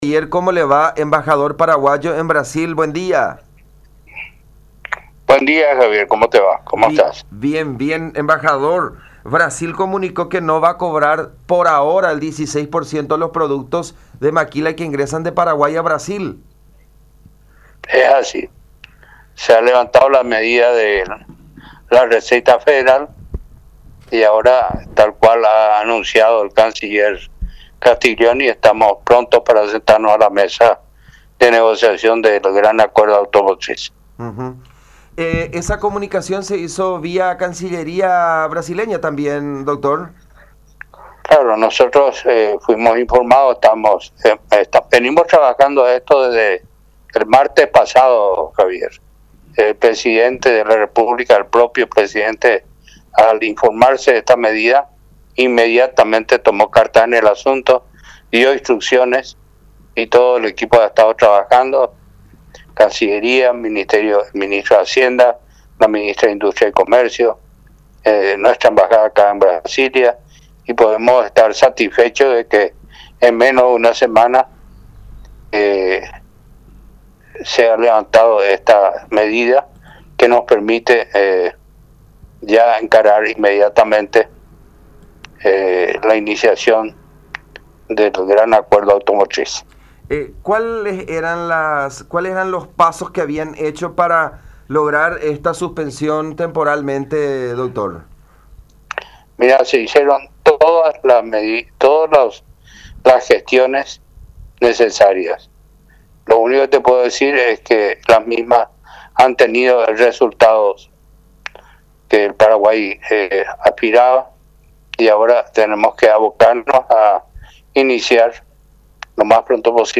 “En menos de una semana se ha podido levantar esta medida, lo cual nos permite ya encarar inmediatamente el inicio de las negociaciones de un gran acuerdo automotriz”, expresó Saguier en comunicación con La Unión.